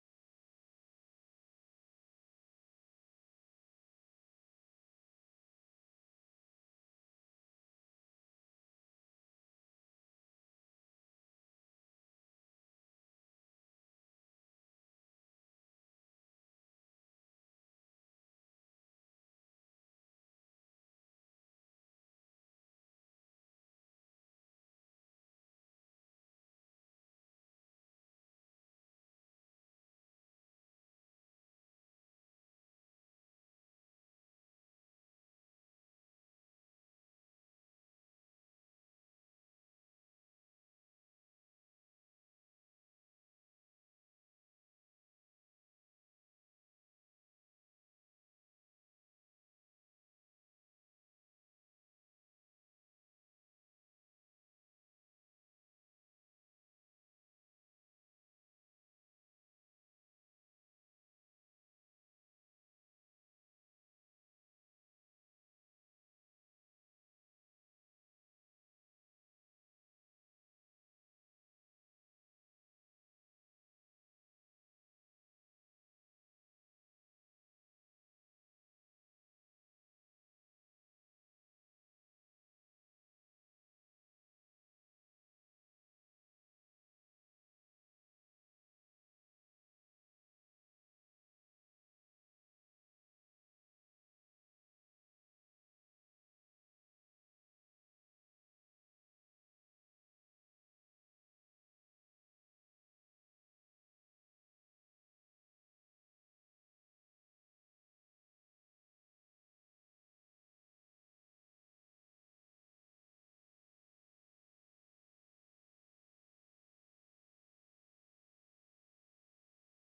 Sermon
Special Guest Speaker